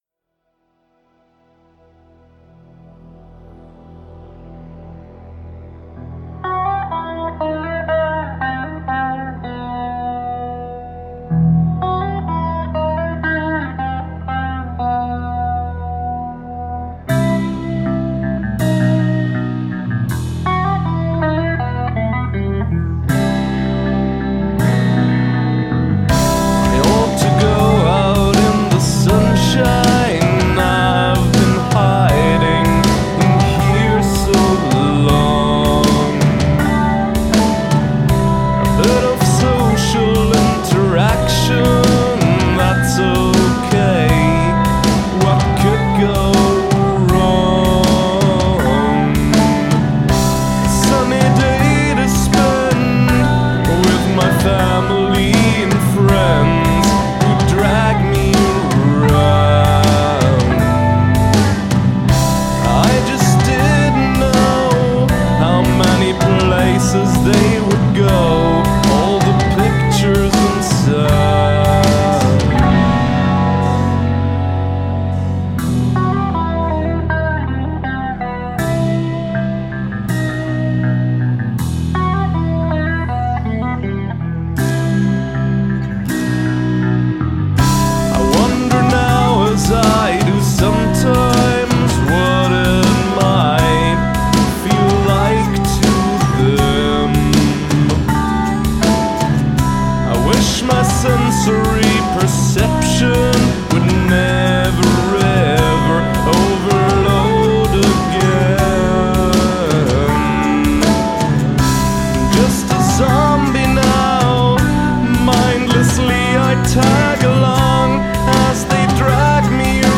Nice atmosphere created by the first 15 seconds.